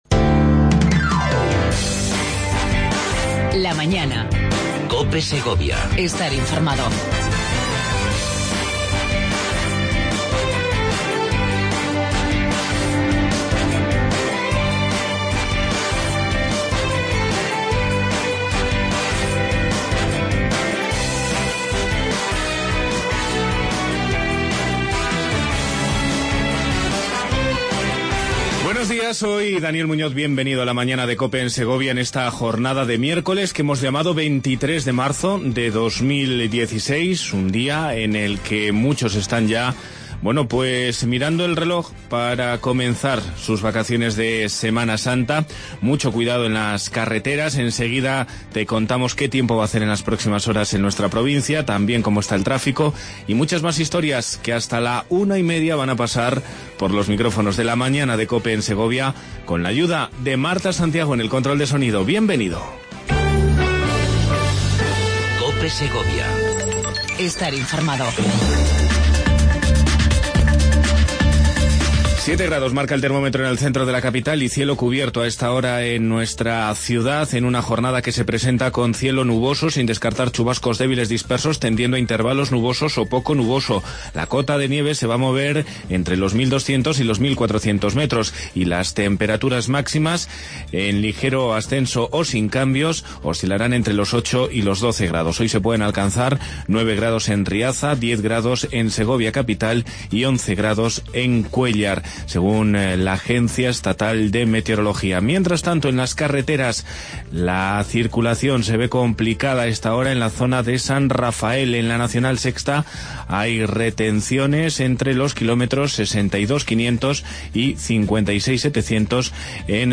AUDIO: Entrevista con Javier López Escobar, delegado territorial de La Junta de Castilla y León.